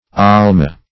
Almah \Al"mah\, n.